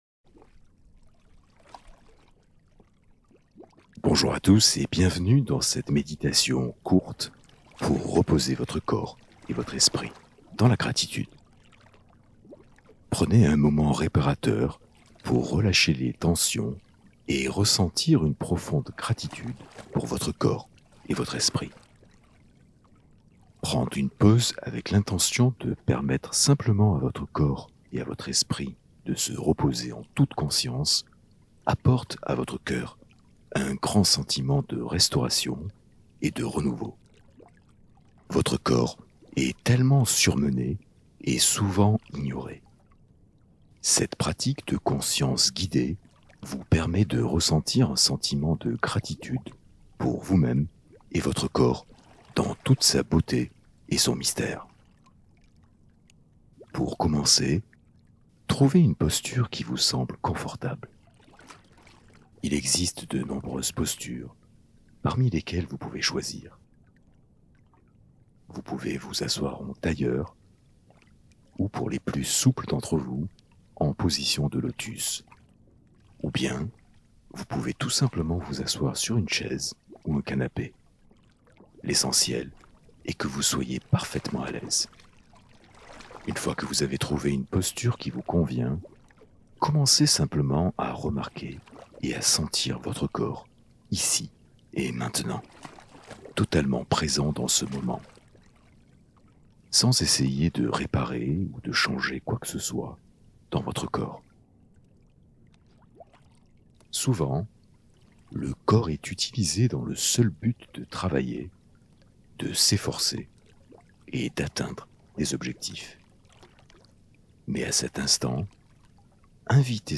Méditation guidée pour apaiser ton corps et ton esprit dans la gratitude